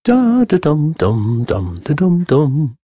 Звуки пения
Звук радостного пения человека в хорошем настроении (та-та-дам)